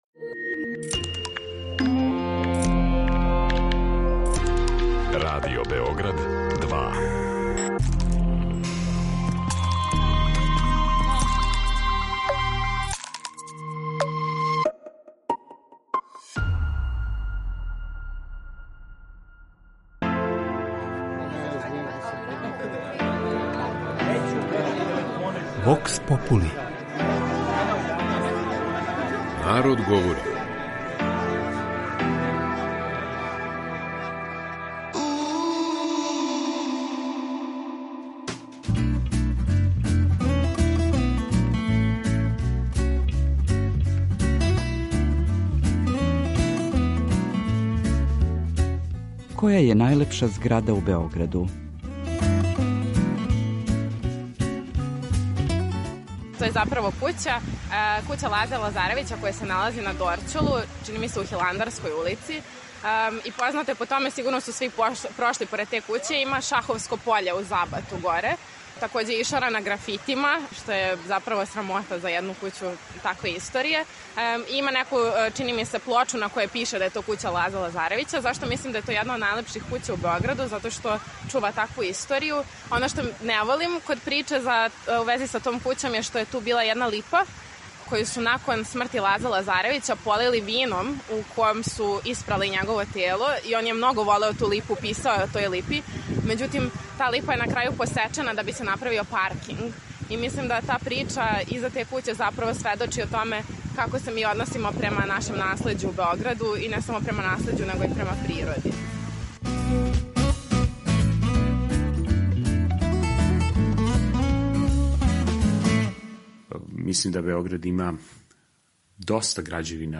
Питали смо Београђане која зграда је, по њиховом мишљењу, најлепша у нашем главном граду.
Вокс попули